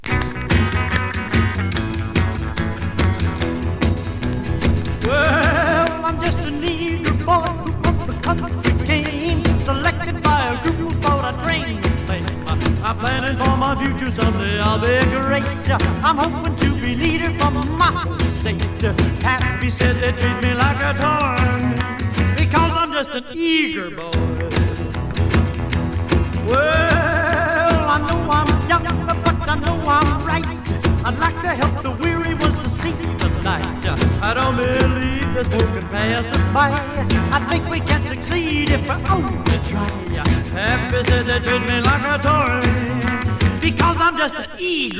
Rock, Rockabilly　UK　12inchレコード　33rpm　Mono